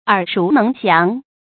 耳熟能詳 注音： ㄦˇ ㄕㄨˊ ㄣㄥˊ ㄒㄧㄤˊ 讀音讀法： 意思解釋： 聽得多了；就可以說得詳盡細致。